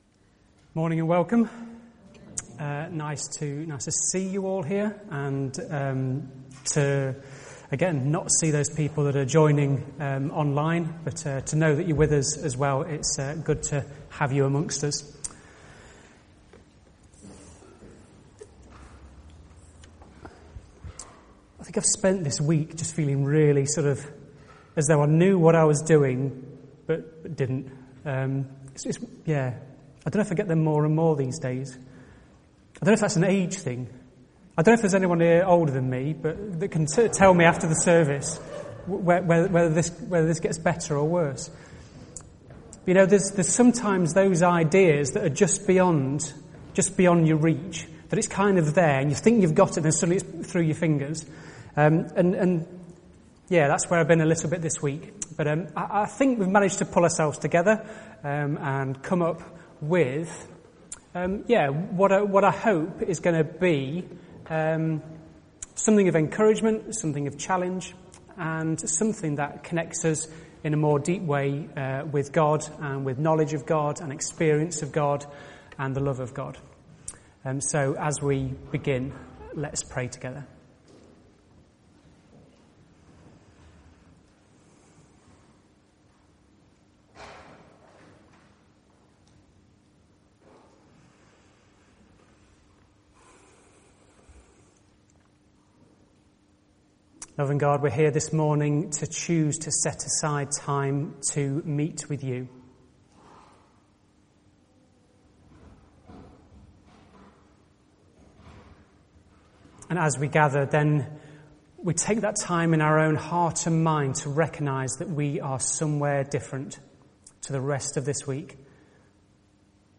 A message from the series
From Service: "10.30am Service"